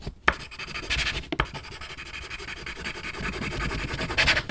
Original creative-commons licensed sounds for DJ's and music producers, recorded with high quality studio microphones.
Scribling with a pencil on a wooden desk, recorded with a TASCAM DR 40.
scribling_with_a_crayon_hn8.wav